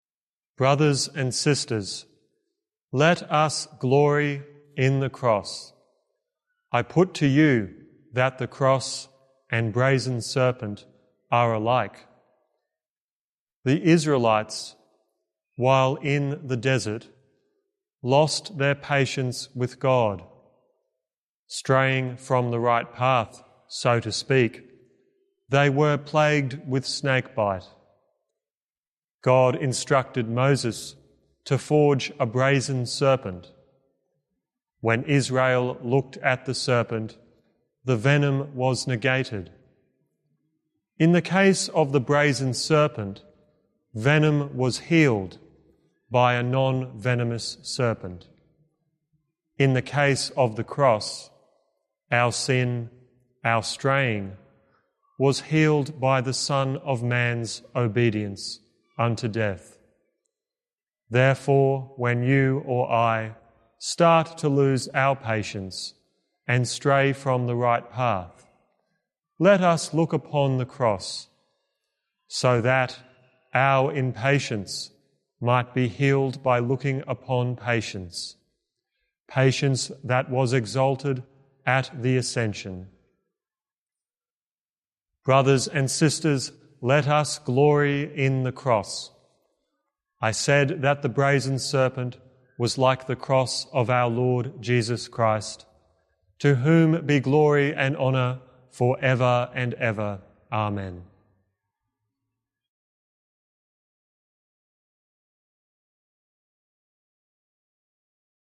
Archdiocese of Brisbane Feast of the Exaltation of the Holy Cross - Two-Minute Homily